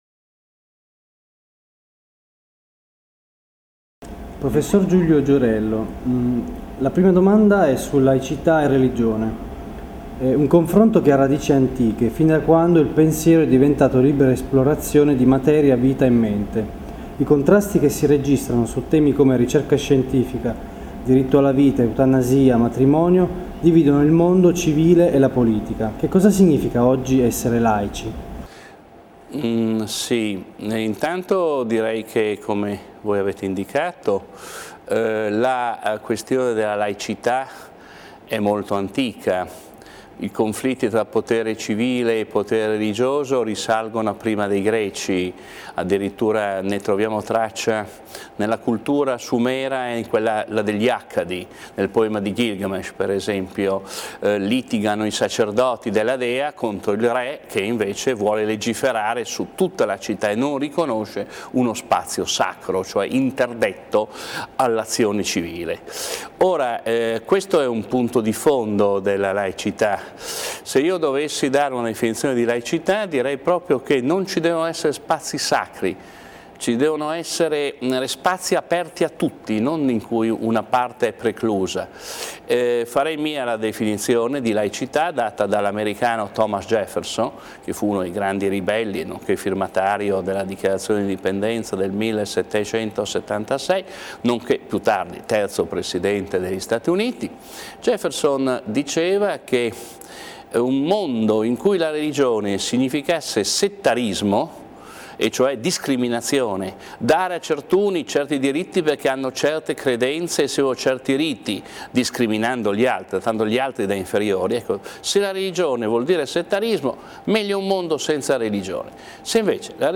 Intervista a Giulio Giorello, professore di Filosofia della Scienza